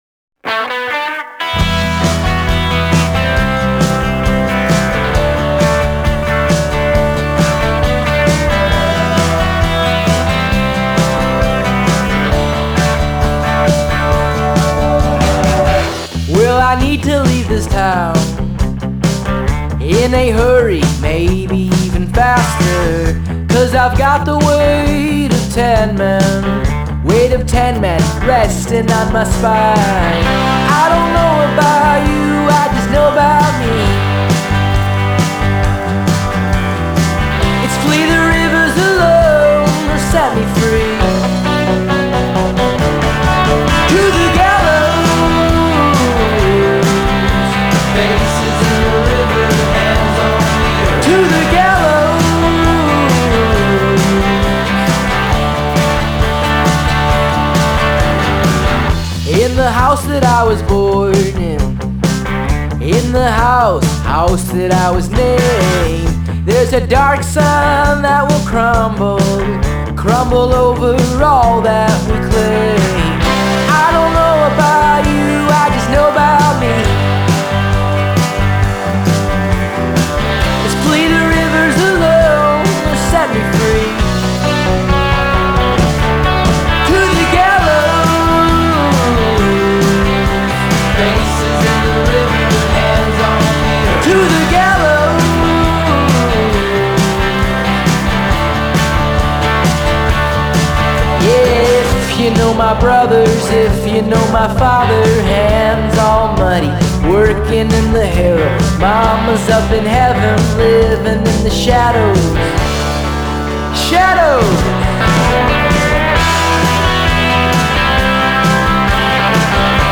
Genre: Alt Folk, Americana